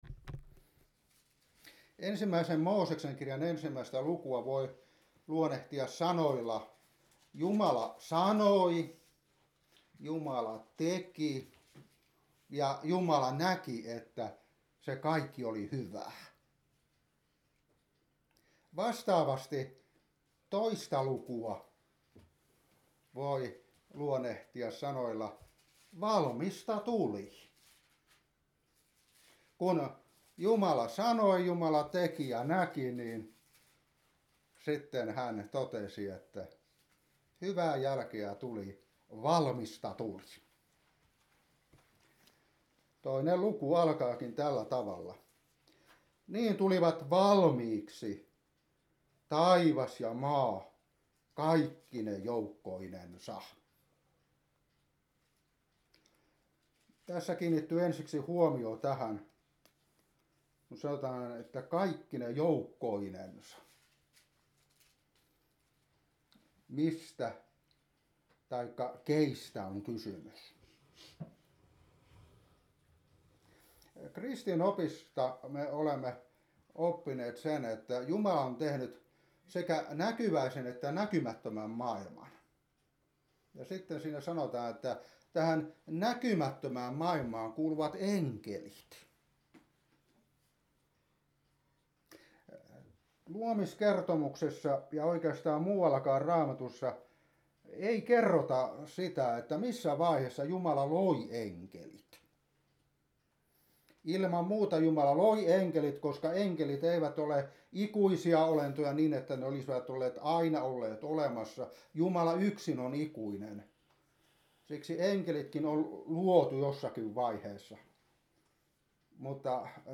Saarnasarana (1.Moos.2) Valmista tuli
Kauhajoki